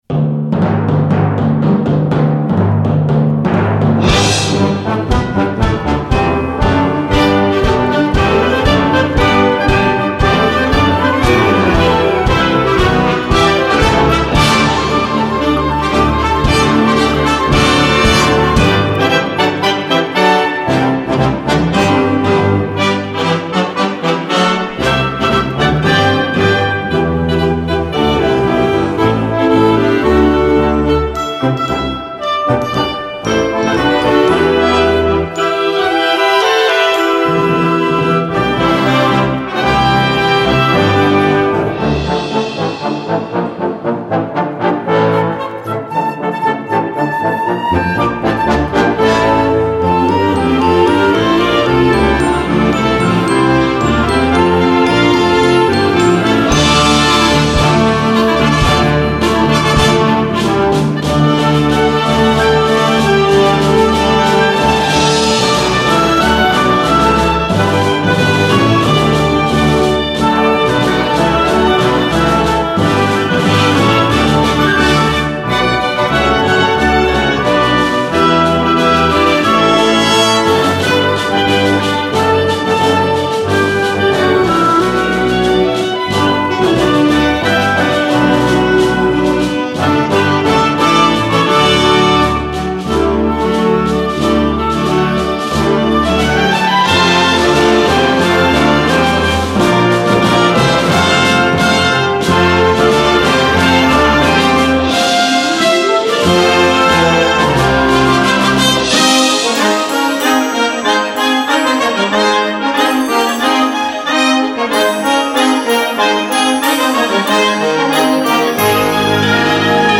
Gattung: Weihnachtliche Musik
Besetzung: Blasorchester